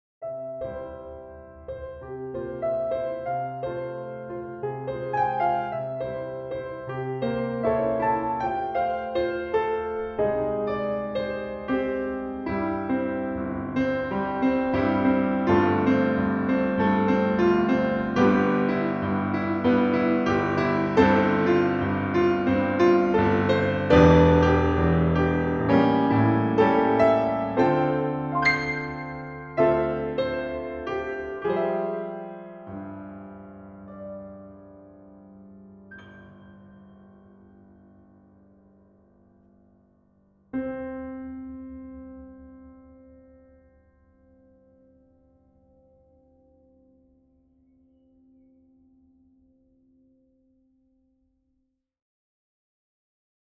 Вот, например, EastWest Bosendorfer, ни одного плагина, никакой обработки - ничего.
По мотивам темы топикстартера Вложения Piano.mp3 Piano.mp3 2 MB · Просмотры: 350